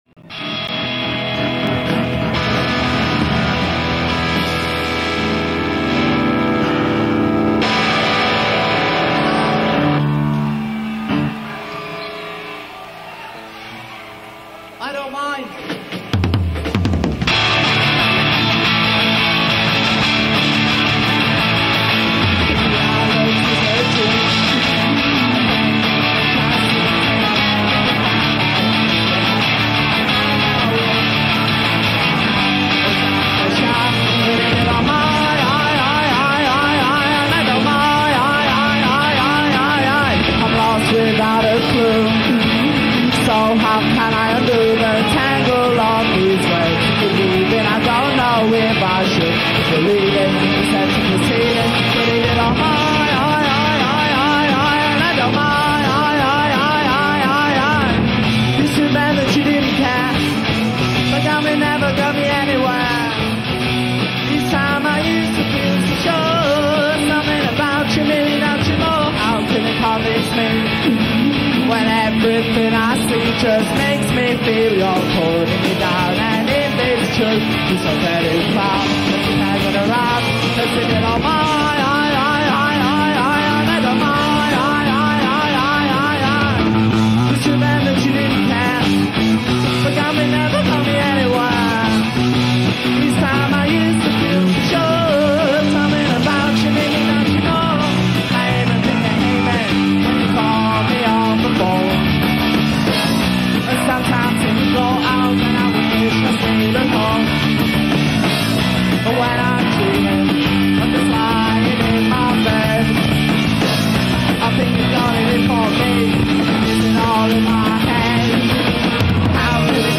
Live At Temple Beautiful